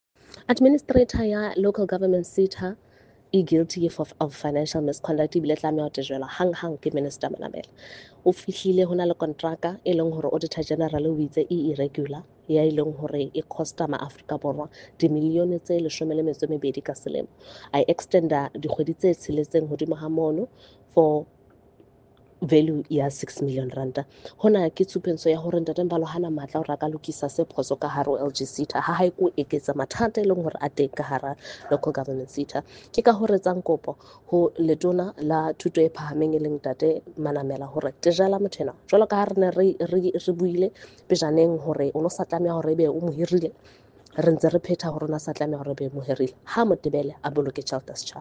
isiZulu soundbites by Karabo Khakhau MP.